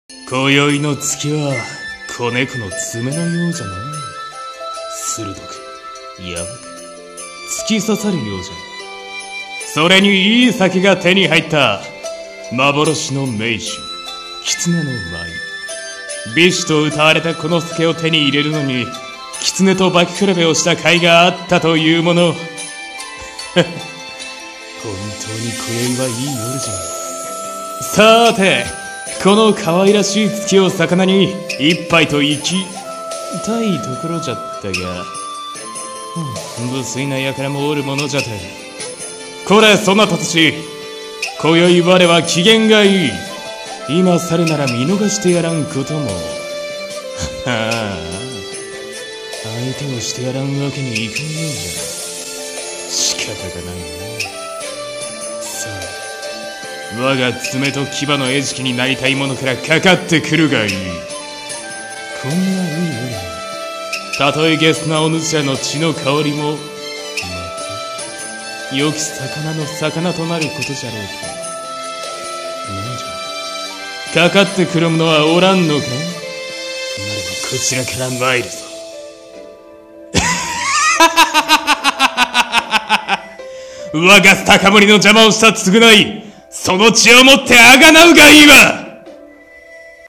【声劇台本】化け猫の舞